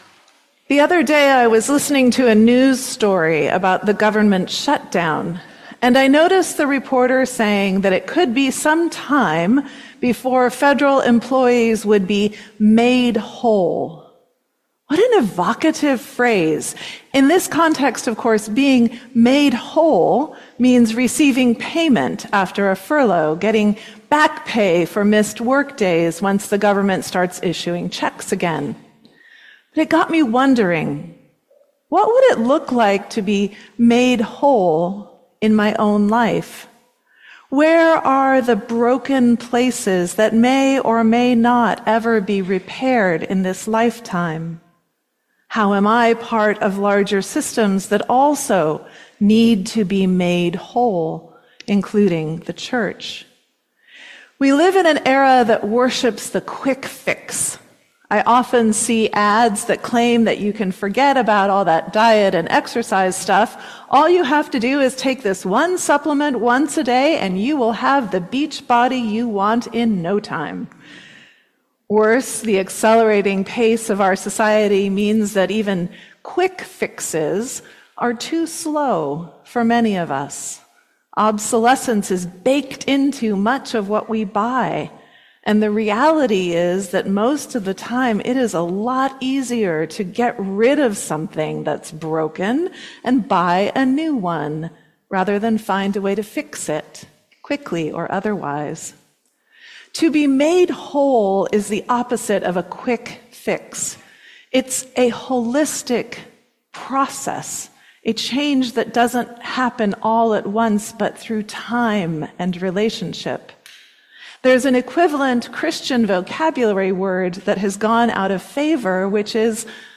Sermon on October 12